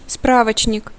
Ääntäminen
IPA: [ma.nɥɛl]